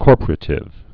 (kôrpər-ə-tĭv, -pə-rātĭv)